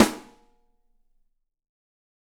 20 4X14 OH-L.wav